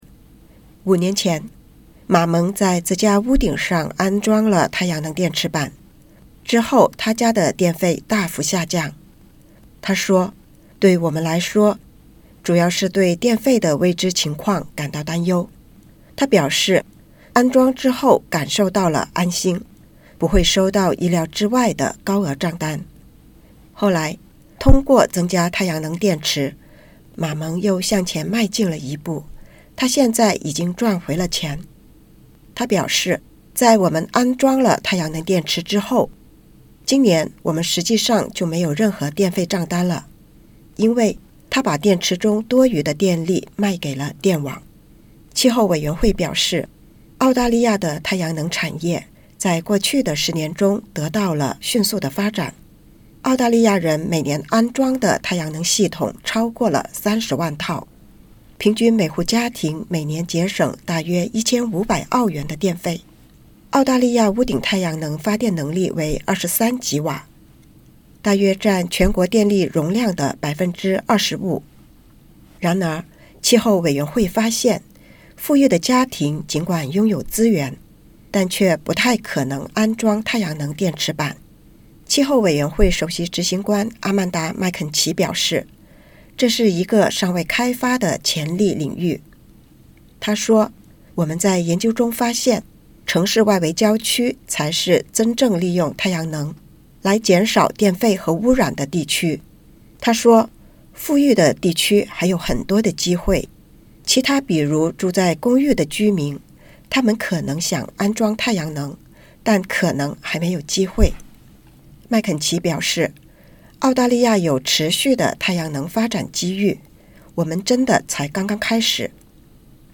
气候委员会表示，过去十年来，澳大利亚的太阳能产业发展迅速，平均每个家庭每年节省约1500澳元的电费。请点击 ▶ 收听报道。